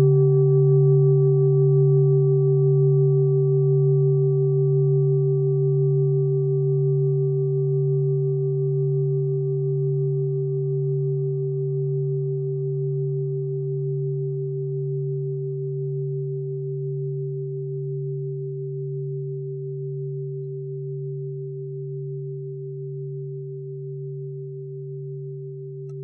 Klangschale Orissa Nr.8
(Ermittelt mit dem Filzklöppel oder Gummikernschlegel)
Diese Frequenz kann bei 160Hz hörbar gemacht werden; das ist in unserer Tonleiter nahe beim "E".
klangschale-orissa-8.wav